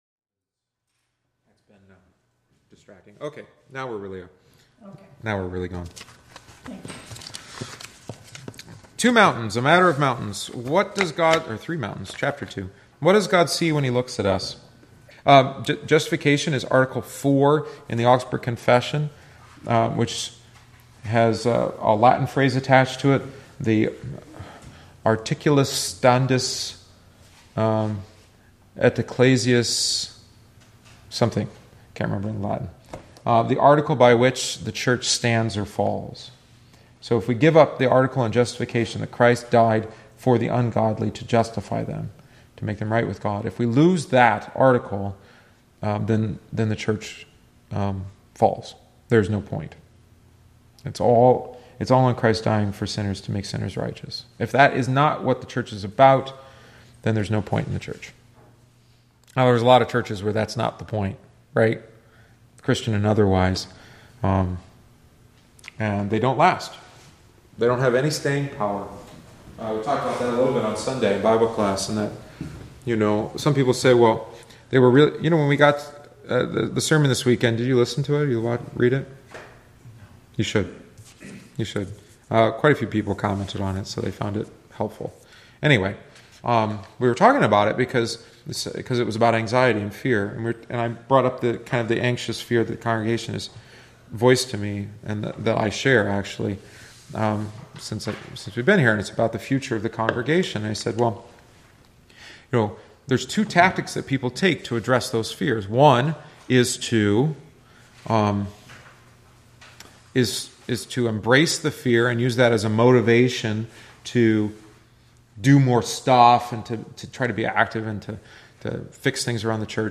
The attached audio omits the text of the book and includes only our discussion.